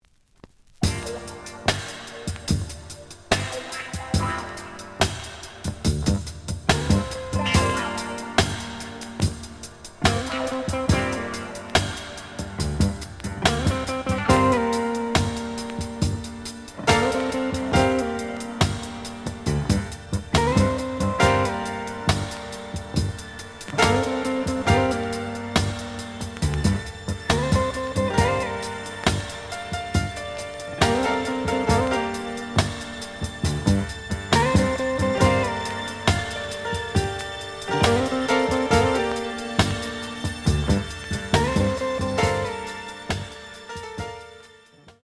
The audio sample is recorded from the actual item.
●Genre: Disco
Some click noise on B side due to scratches.